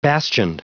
Prononciation du mot bastioned en anglais (fichier audio)
Prononciation du mot : bastioned
bastioned.wav